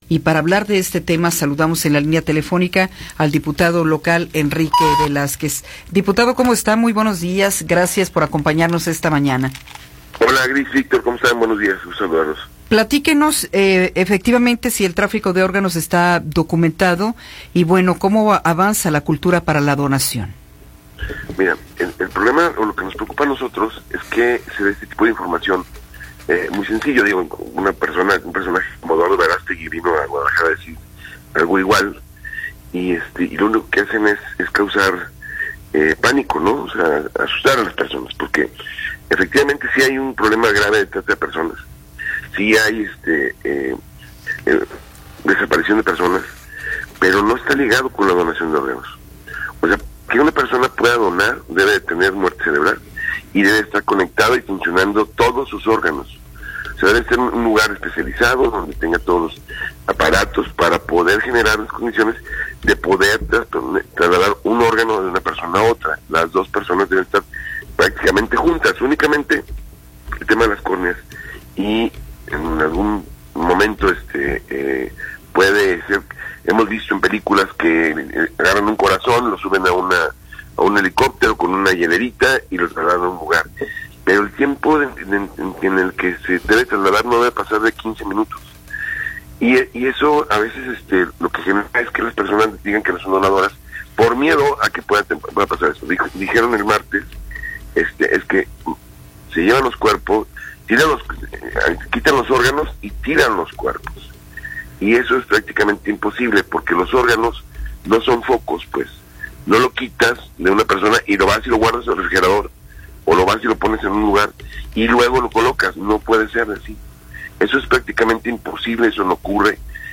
Entrevista con Enrique Velázquez
Enrique Velázquez, diputado local, nos habla sobre la donación de órganos y la propuesta de matrimonios temporales.